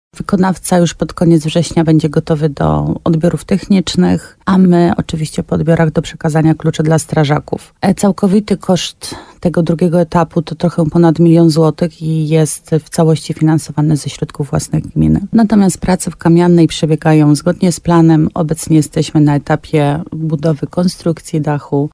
– mówi wójt gminy Łabowa Marta Słaby.